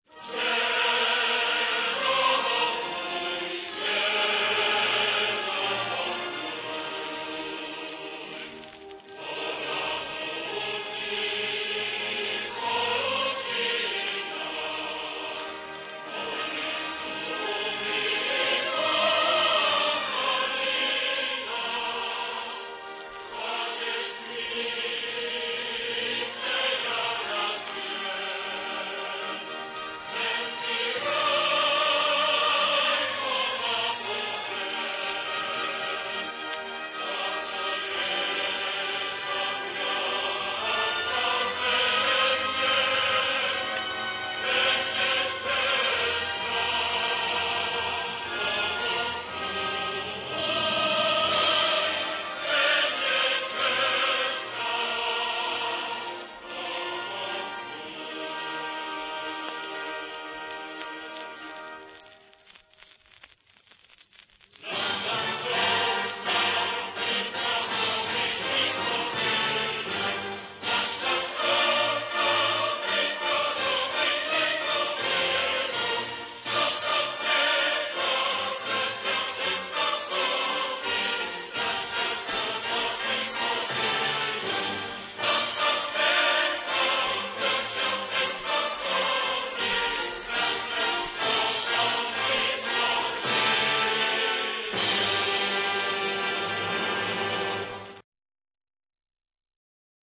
捷 克 斯 洛 伐 克 自 成 立 以 來 至 分 家 前 ， 所 用 國 歌 即 為 現 今 捷 克 與 斯 洛 伐 克 兩 國 國 歌 的 合 組 ， 兩 者 曲 風 完 全 不 同 ， 故 在 當 時 為 所 有 國 歌 中 曲 風 變 化 最 大 的 一 首 。
歌唱版RA